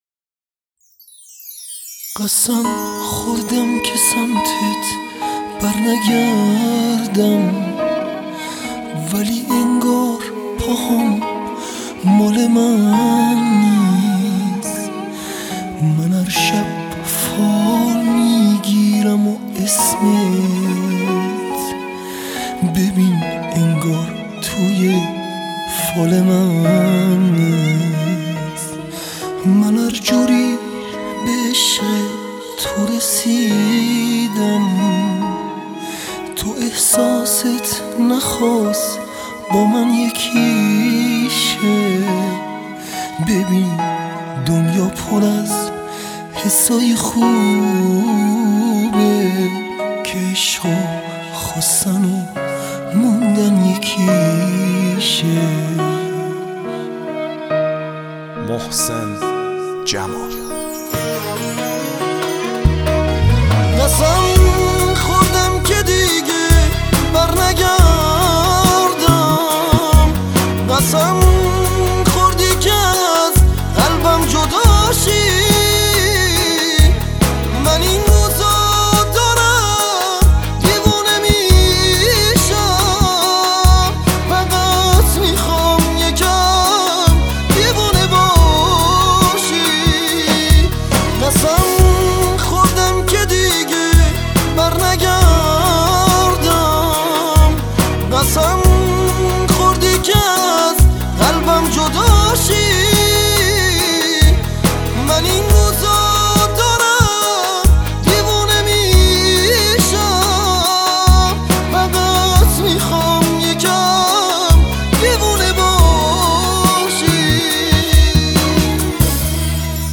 اروم و قشنگ
صدای با استعدادی داره